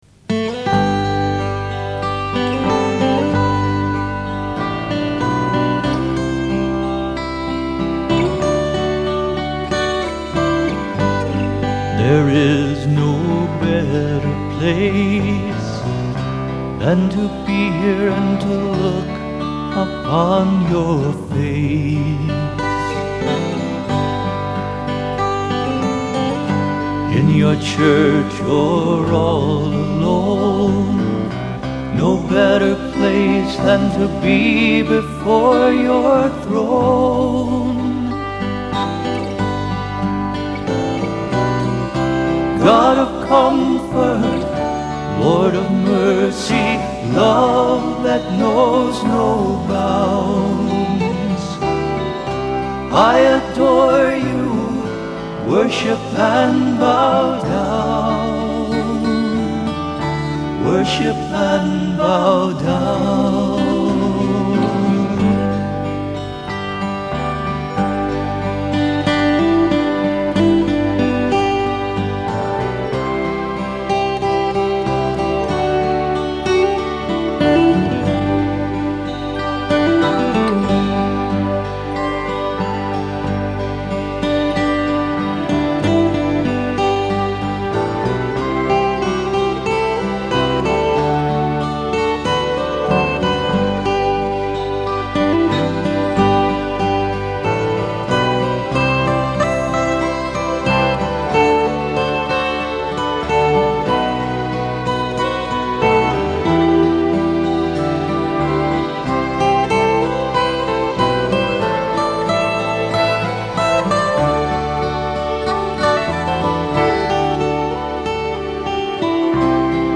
Others' stuff, Worship, Ministry, Music
praise and worship songs